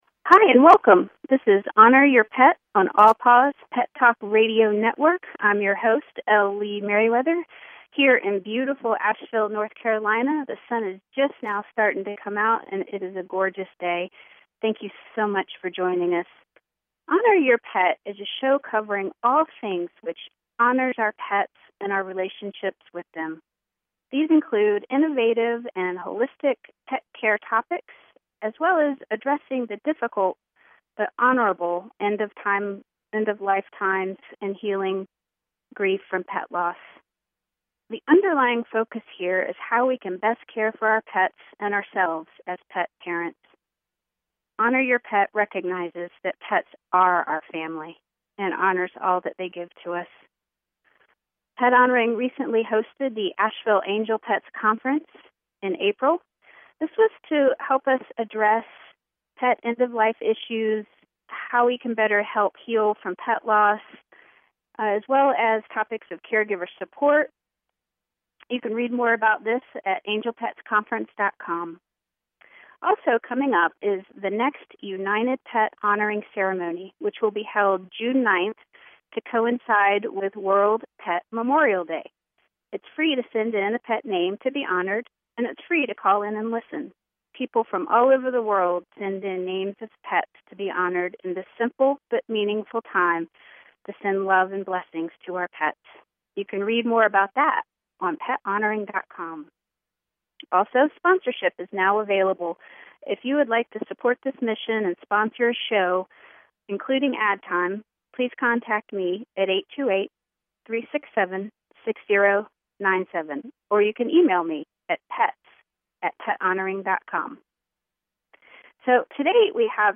Honor Your Pet is a talk radio show covering all things which honor our pets and our relationship with them. These include innovative and holistic pet care topics as well as addressing the difficult, but honorable end-of-life times and healing grief from pet loss.